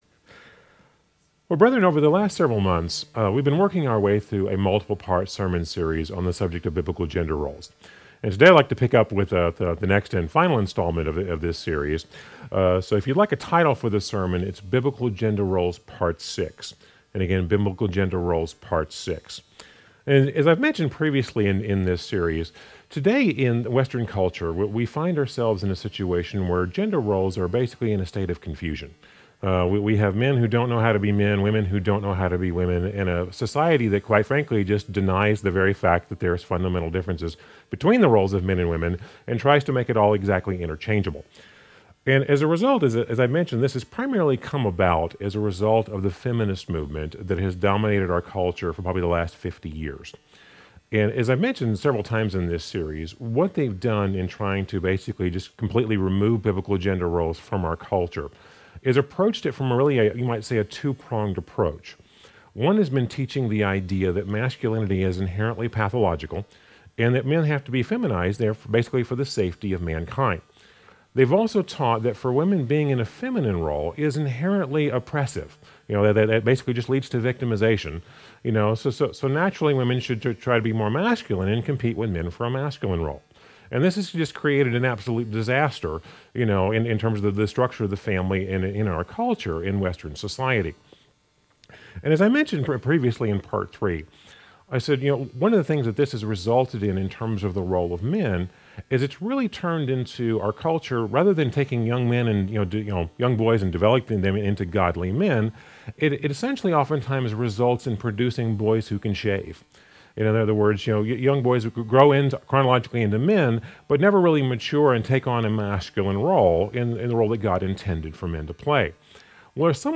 A message from the series "Biblical Gender Roles."